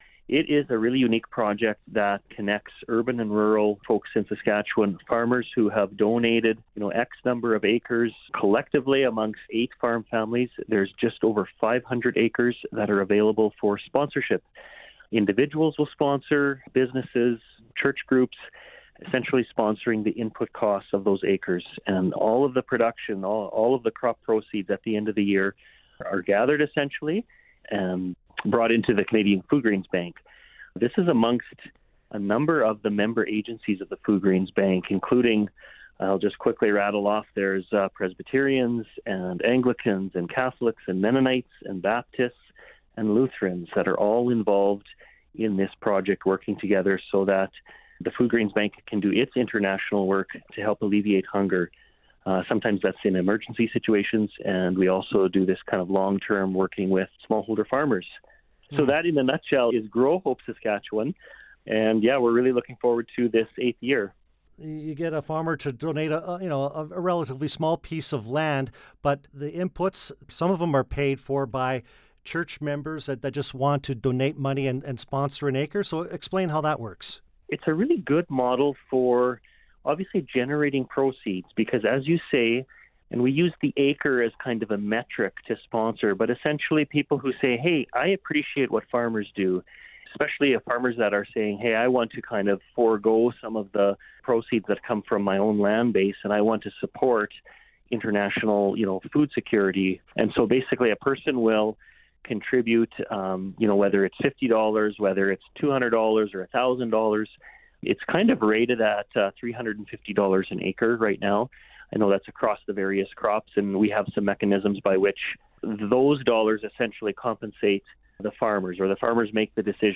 The interview is below and you can learn more about the program here.